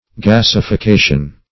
Gasification \Gas`i*fi*ca"tion\, n.